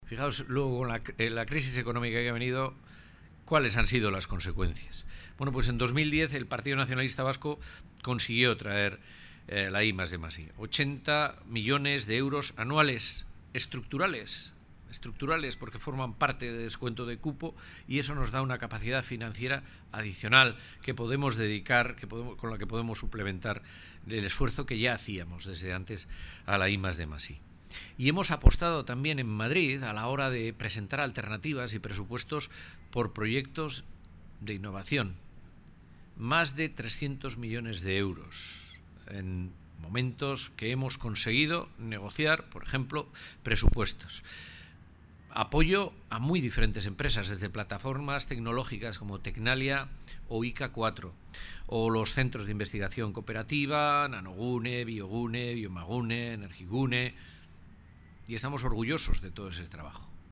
• Esteban: "EAJ-PNV ha impulsado proyectos de innovación por más de 300 millones de euros" 16/06/2016
Amorebieta-Etxano. Acto sectorial Innovación. AIC-Boroa